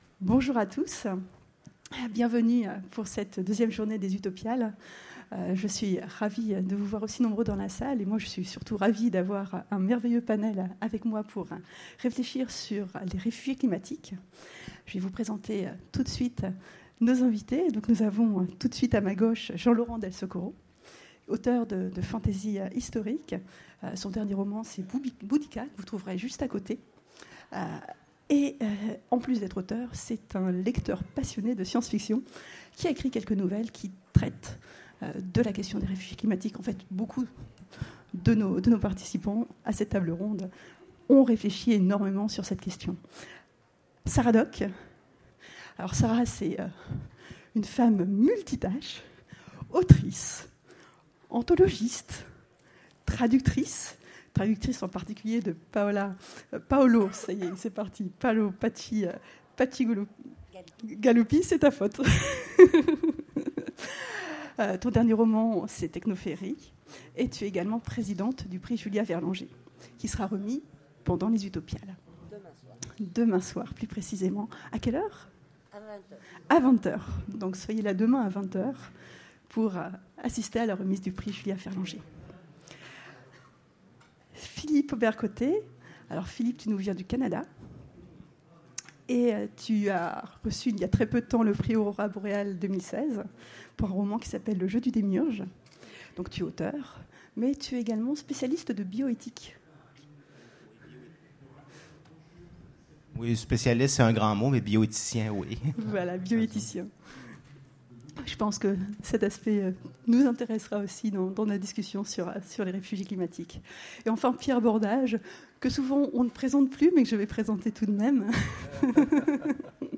Utopiales 2017 : Conférence Réfugiés climatiques